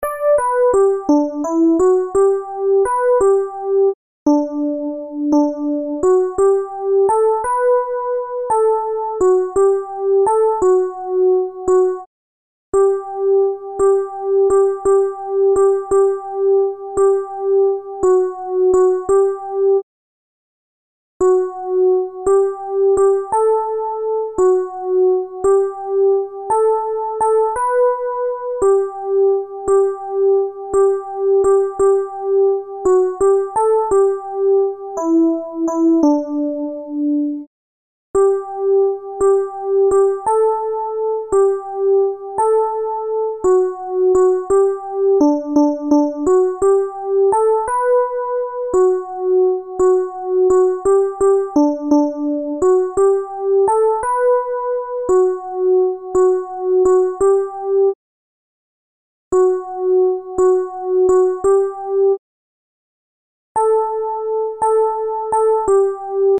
Alti